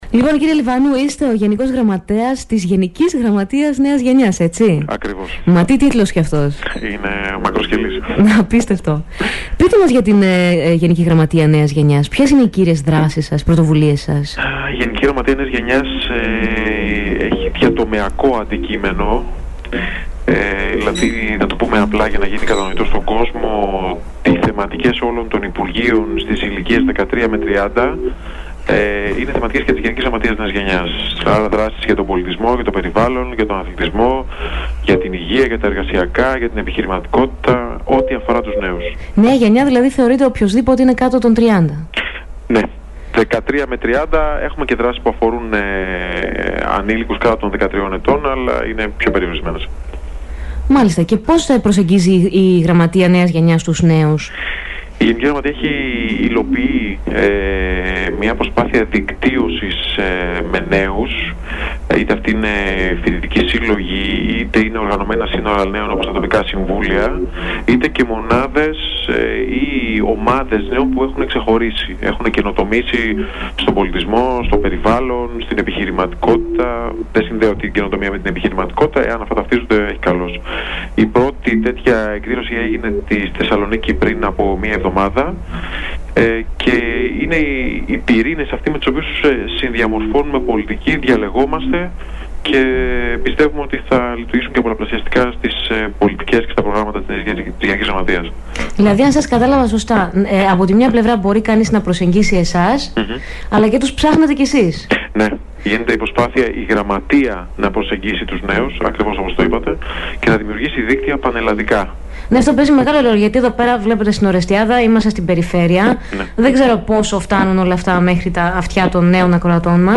Συνέντευξη:Γιάννος Λιβανός Γενικός Γραμματέας της Γενικής Γραμματείας Νέας Γενιάς